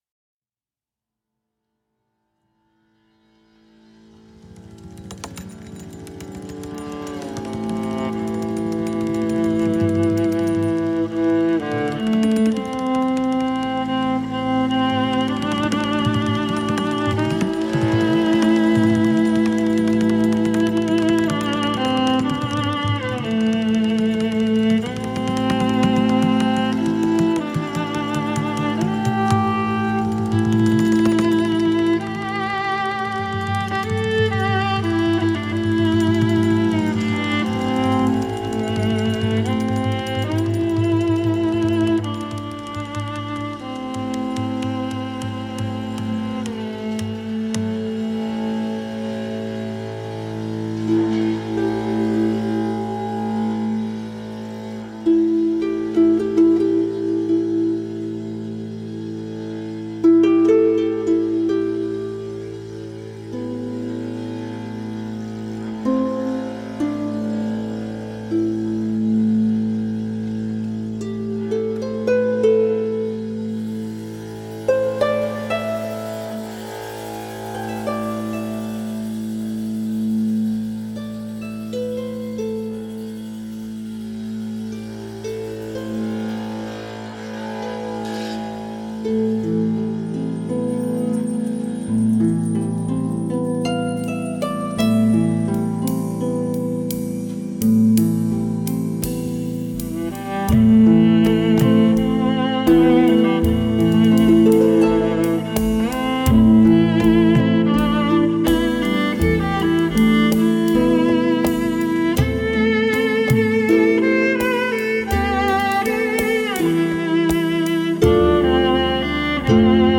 harp, viola and percussion